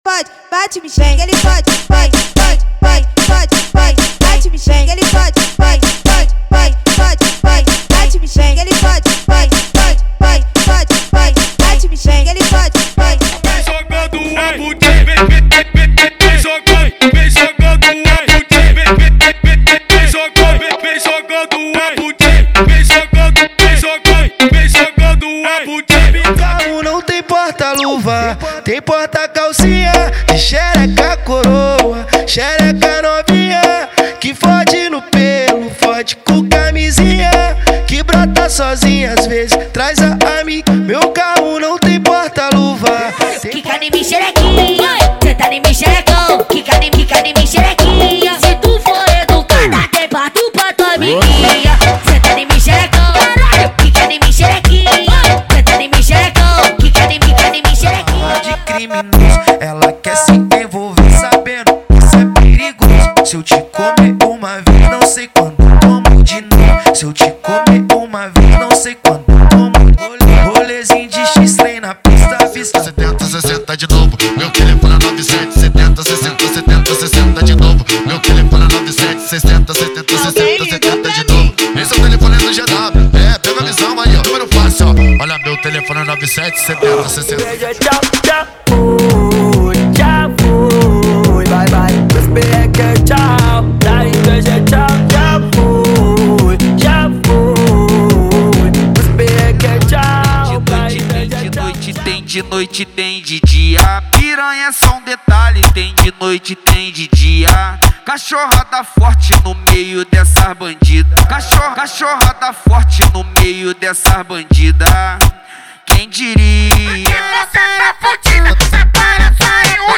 • Funk Proibidão, Funk Rave e Funk Mandelão = 100 Músicas
• Sem Vinhetas
• Em Alta Qualidade